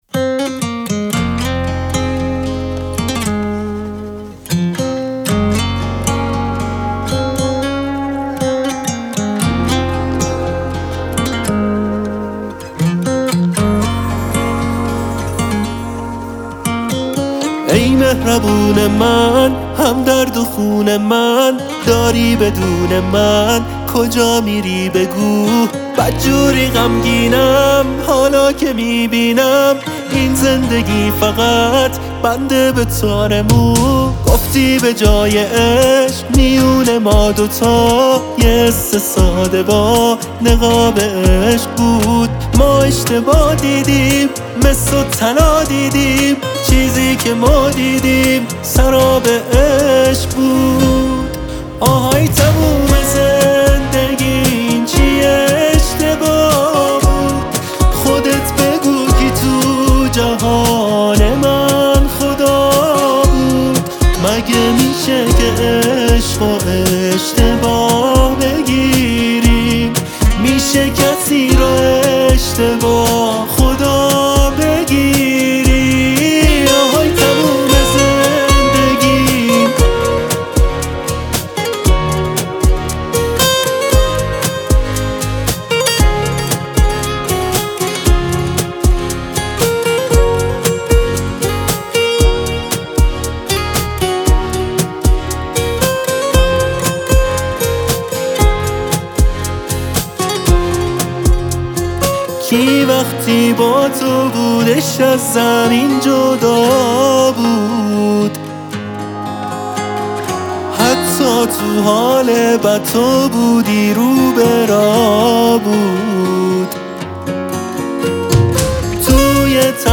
این آهنگ در سبک ژانر اهنگ پاپ خوانده شده است.